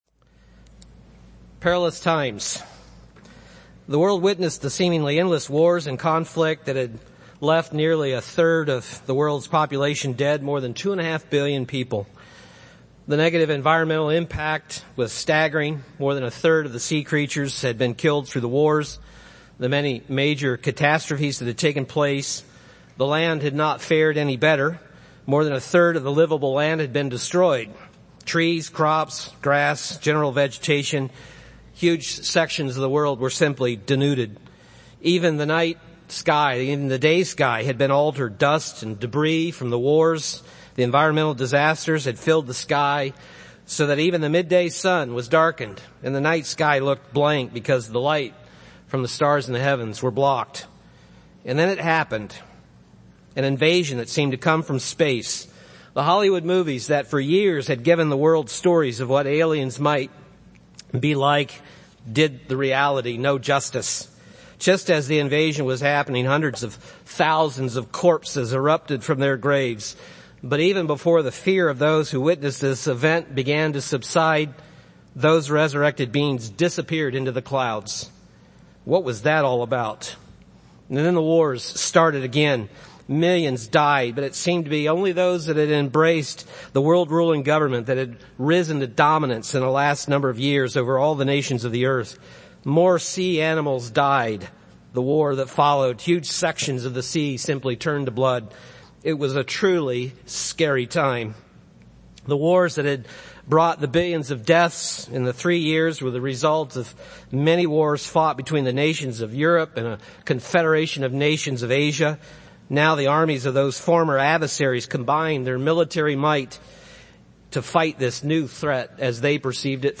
This sermon was given at the Wisconsin Dells, Wisconsin 2015 Feast site.